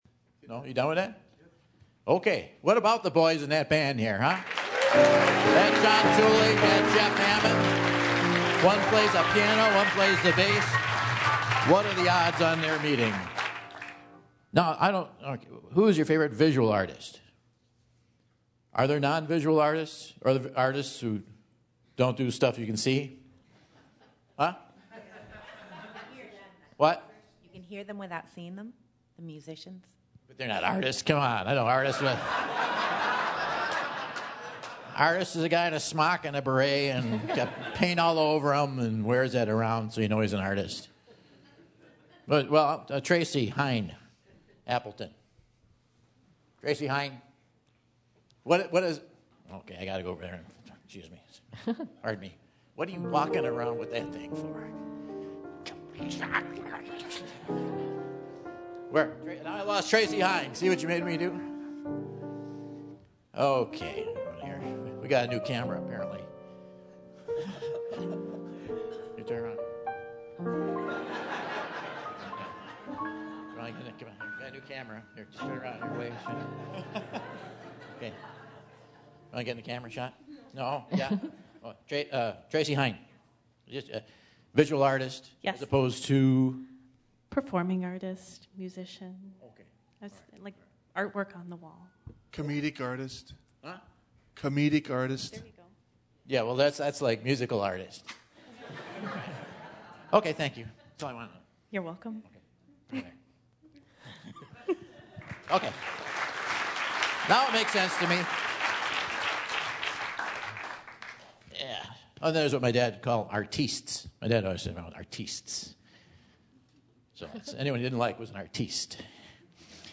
After hearing a pitch perfect Tappet brother impersonation and defending himself against radio-enhancing drugs, Michael finds his first contestant for the Whad'Ya Know? quiz!